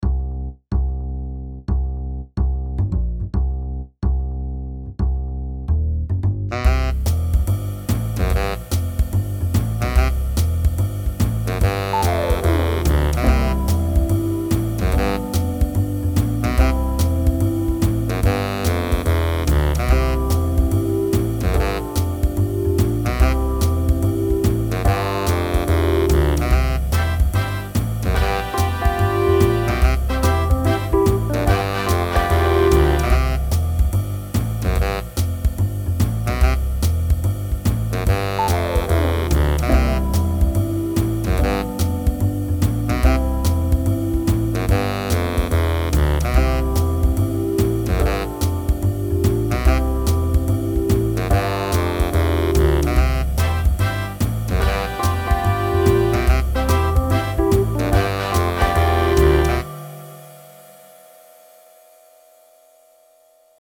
Het KIEMM-lied in karaoke
KIEMMlied_karaoke.mp3